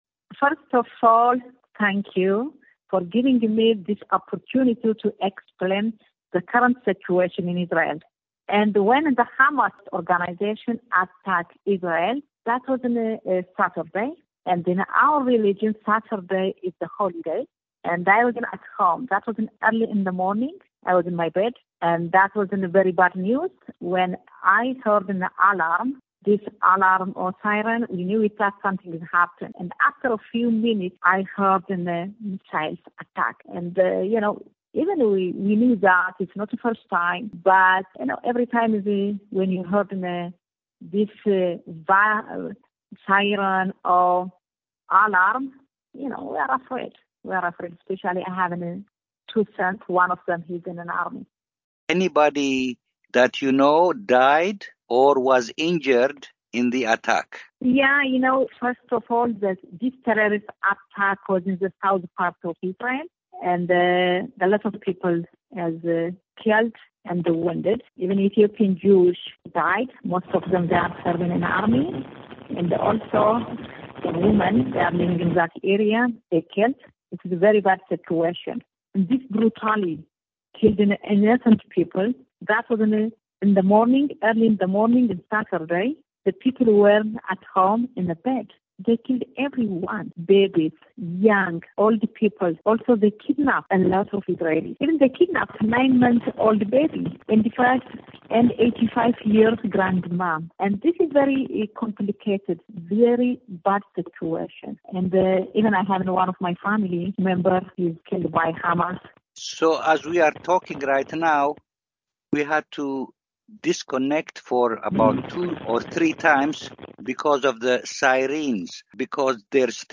Ethiopian Israeli Parliamentarian Describes Hamas Attack